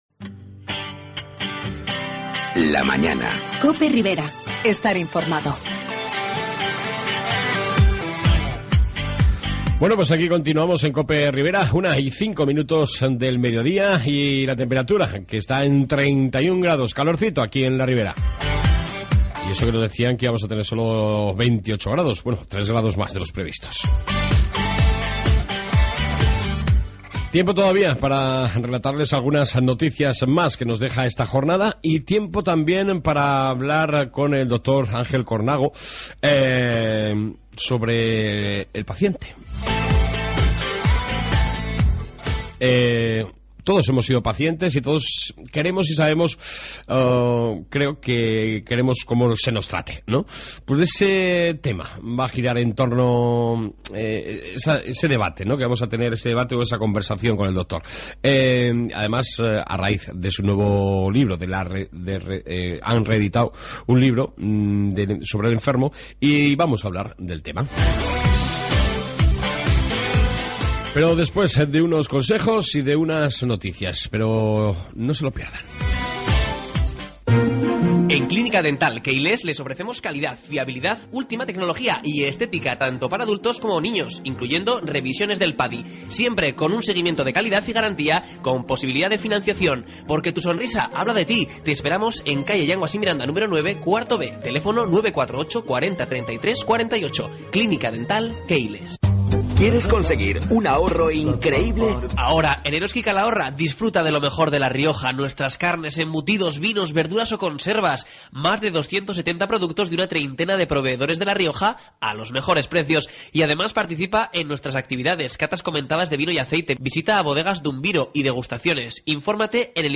AUDIO: ERn esta 2 parte entrevista